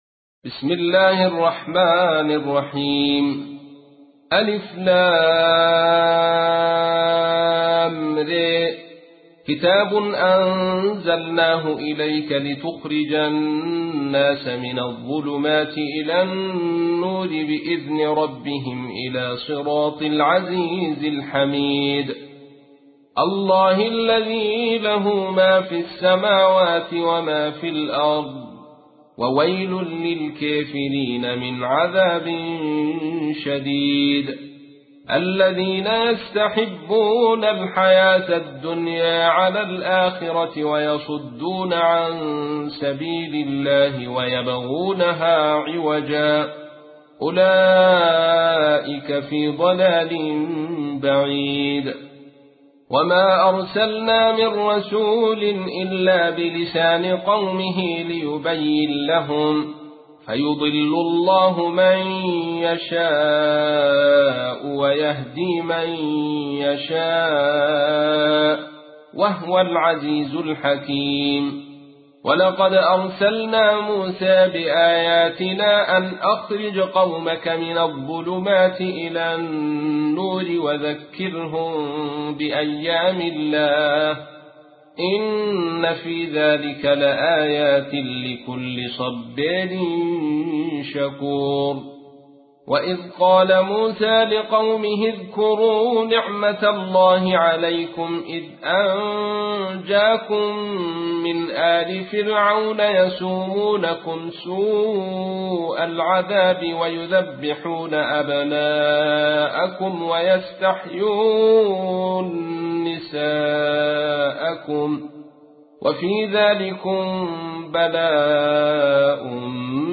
تحميل : 14. سورة إبراهيم / القارئ عبد الرشيد صوفي / القرآن الكريم / موقع يا حسين